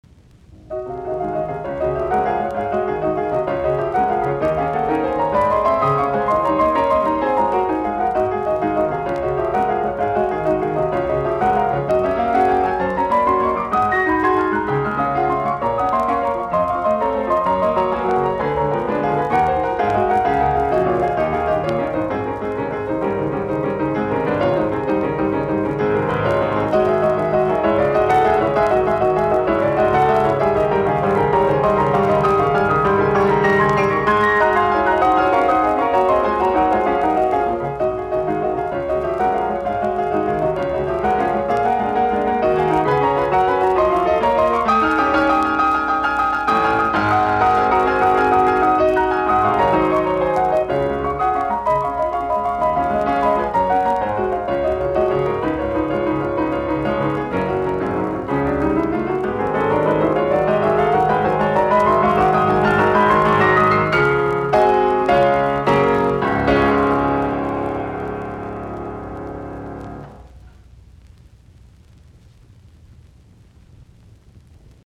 Etydit, piano, op25